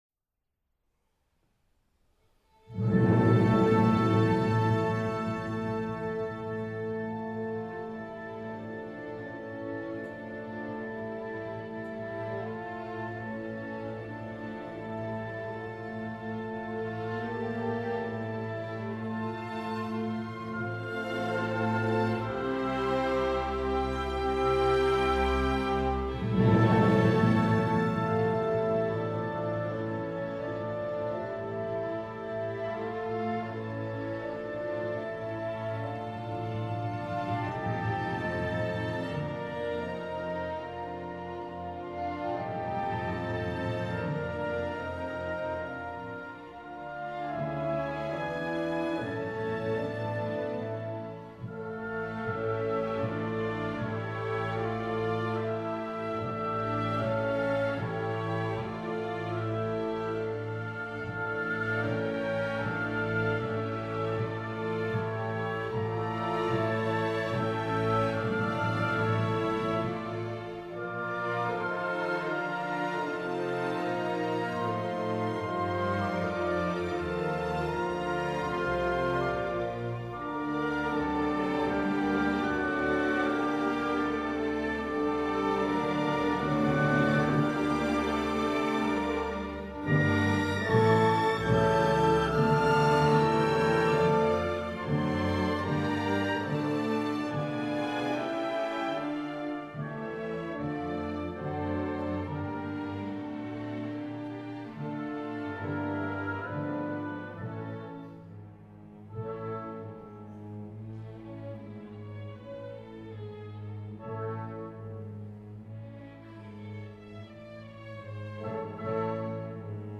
Robert Schumann: Sinfonía nº 4 en re menor, Op. 120
Temporada de abono